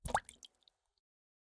46265b6fcc Divergent / mods / Bullet Shell Sounds / gamedata / sounds / bullet_shells / generic_water_2.ogg 23 KiB (Stored with Git LFS) Raw History
generic_water_2.ogg